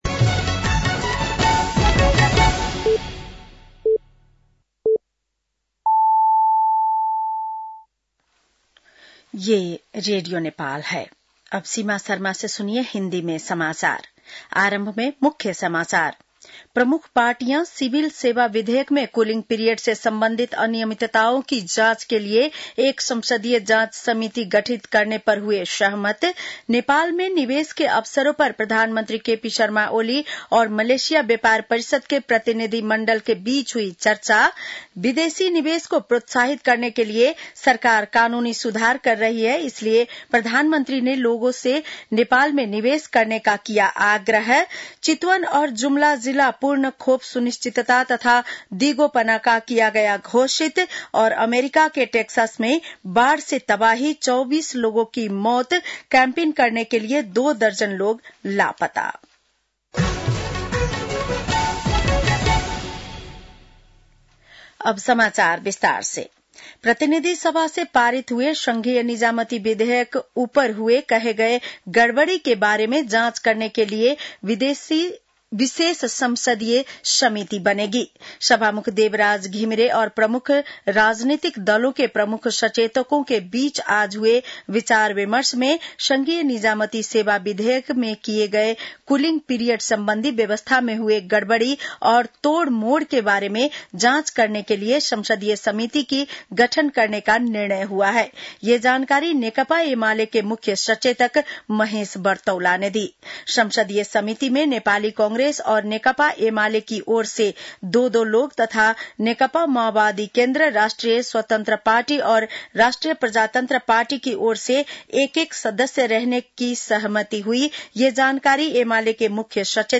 बेलुकी १० बजेको हिन्दी समाचार : २१ असार , २०८२
10-pm-news-.mp3